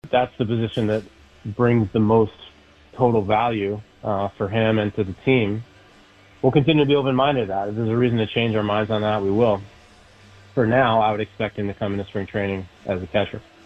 On The Ben Cherington Show on WCCS, the Pirates’ general manager commented on a couple of Bucs who have ailing elbows: catcher Endy Rodriguez and pitcher Jared Jones.